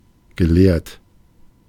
Ääntäminen
Ääntäminen France: IPA: [a.ka.de.mik] Haettu sana löytyi näillä lähdekielillä: ranska Käännös Ääninäyte Adjektiivit 1. akademisch 2. gelehrt 3. gebildet 4. zur Akademie gehörig Suku: f .